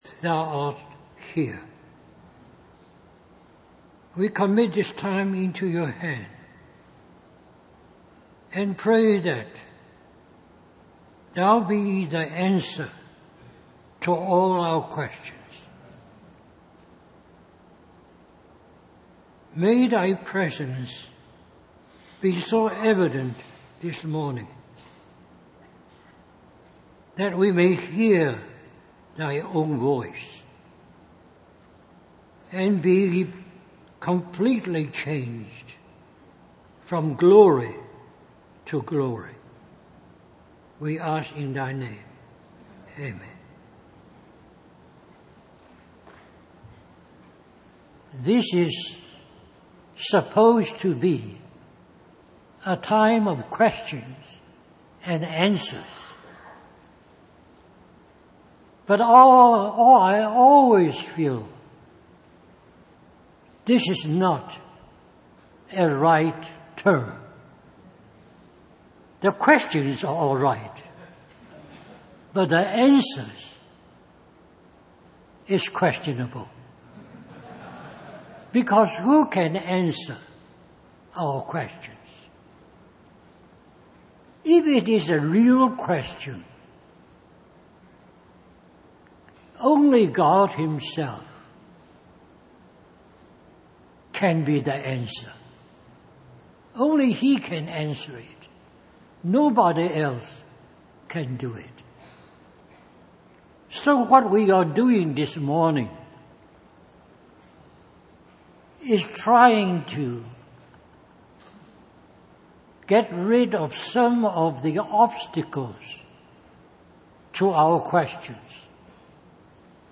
Question and Answer
Harvey Cedars Conference